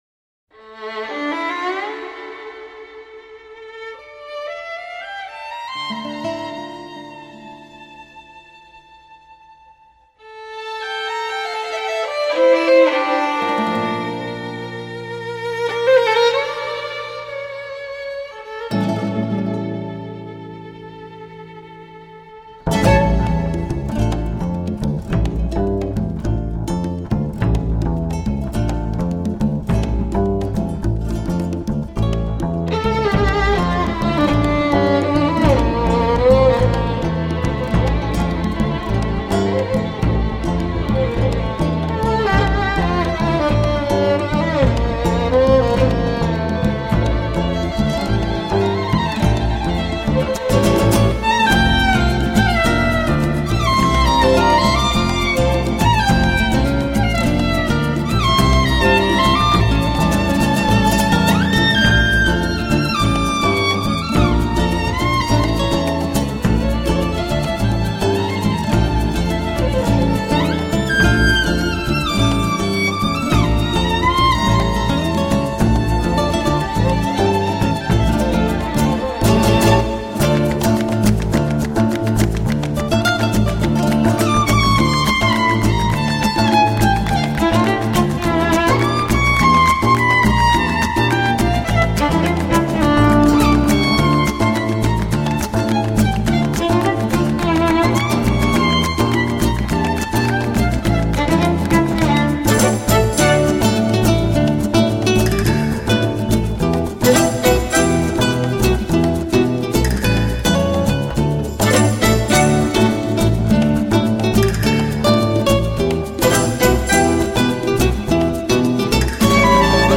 音乐风格主要以西班牙古典旋律与南美的音乐节奏相结合
融合了古典与浪漫的气质，流行与动感的风格